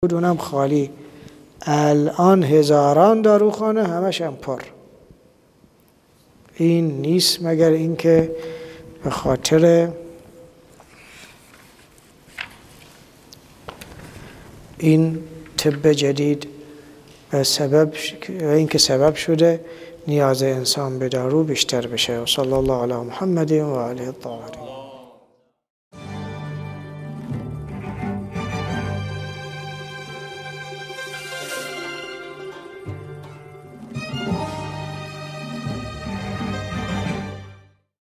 صوت تدریس جلد2 ، جلسه 3